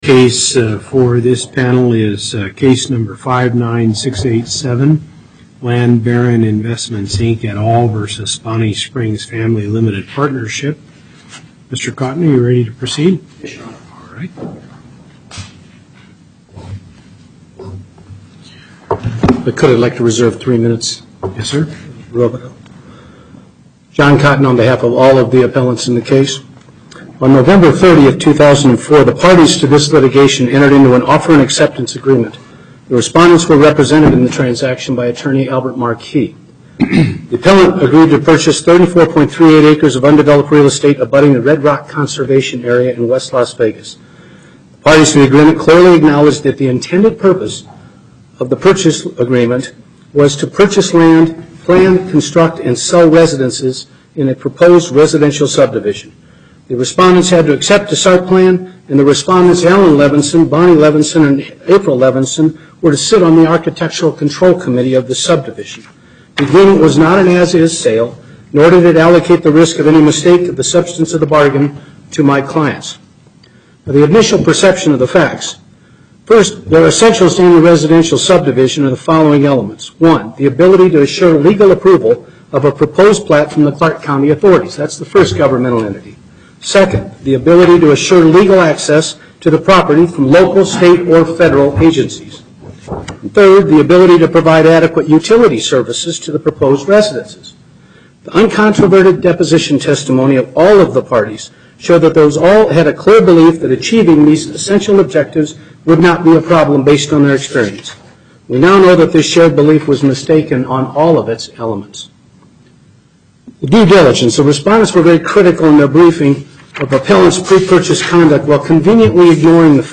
Location: Carson City Before the Northern Nevada Panel, Justice Hardesty presiding